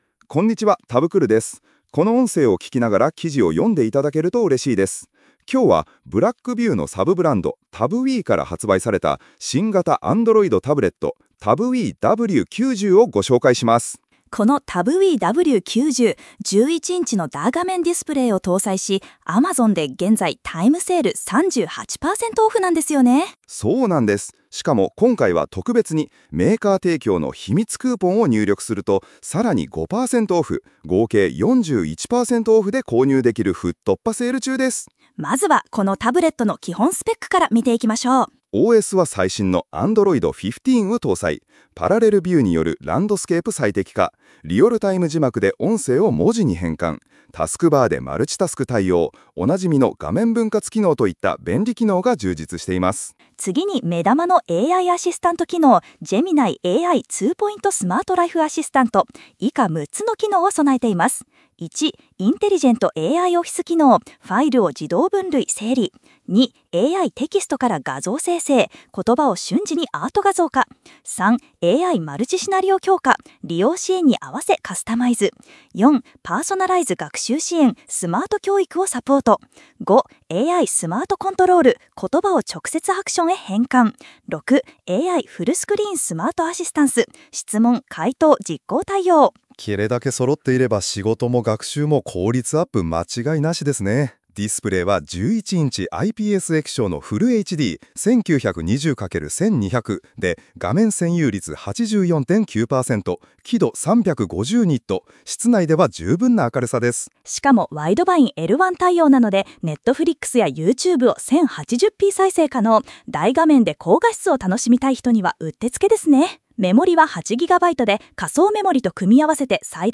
記事の内容をラジオ風に聴くことができます。